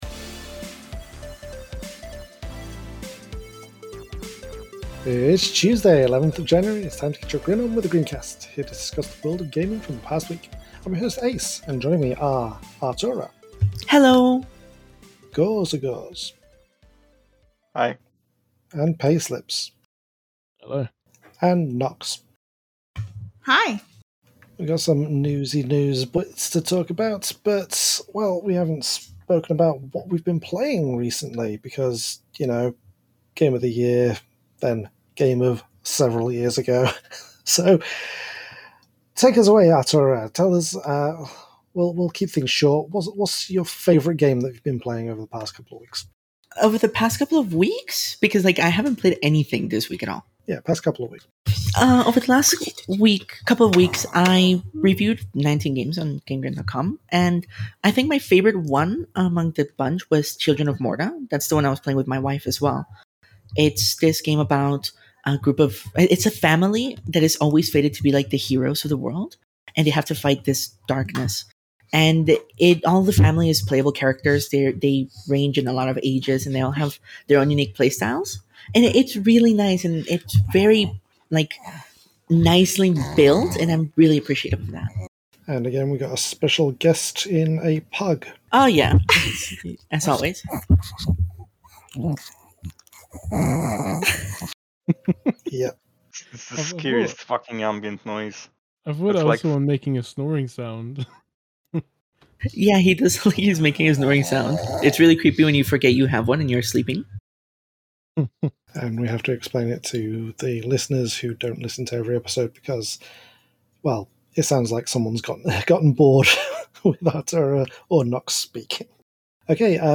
Featuring a special growl from Sir Murkalot the pug.